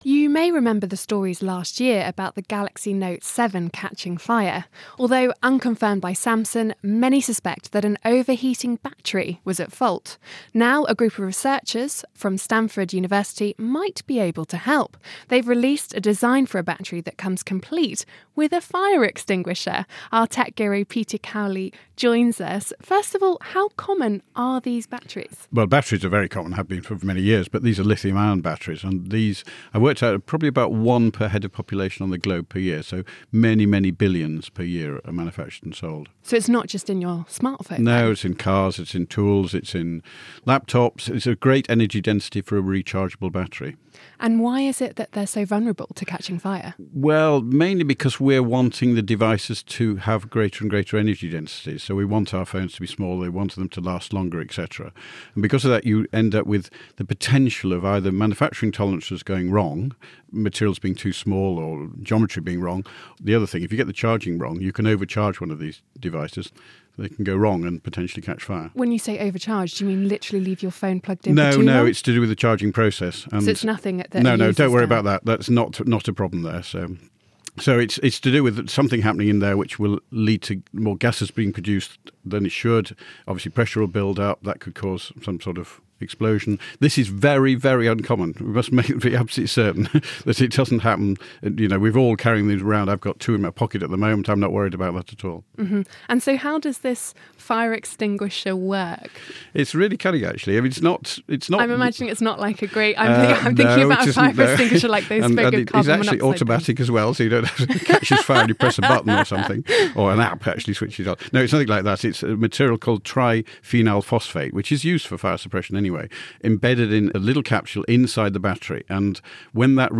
Tech Investor